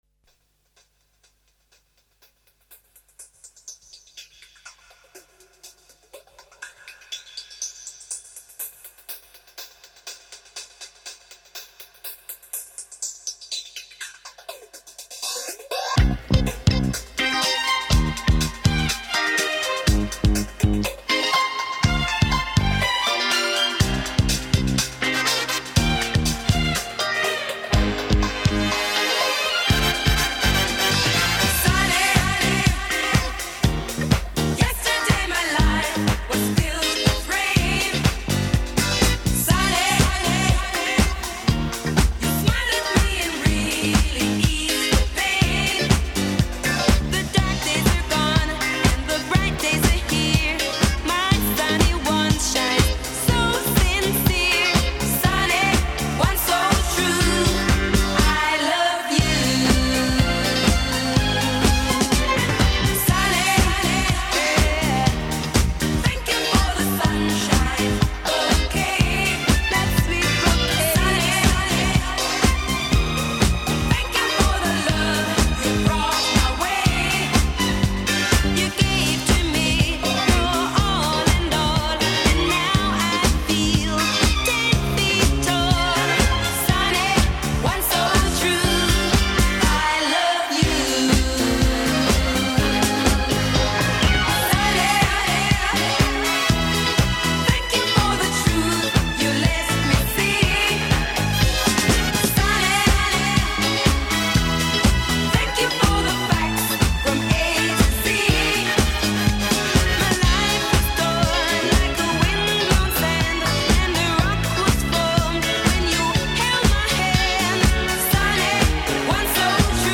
Filed under classic, disco